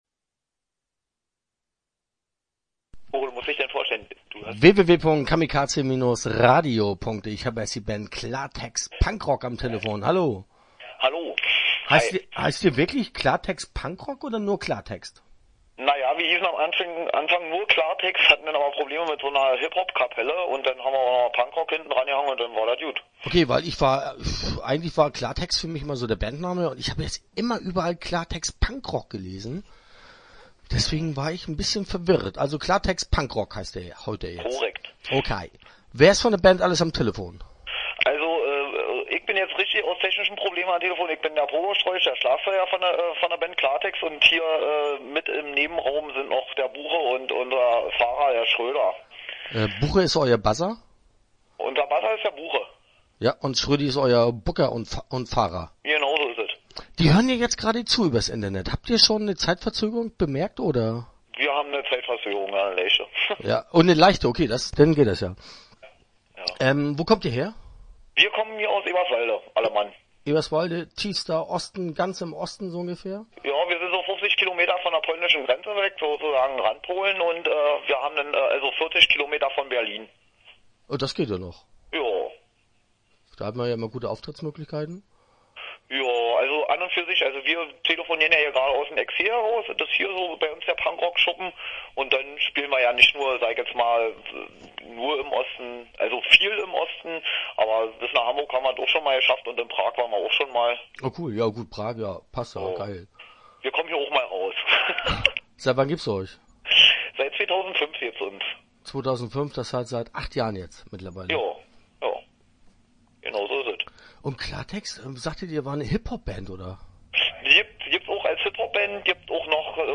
Start » Interviews » Klartext PunkRock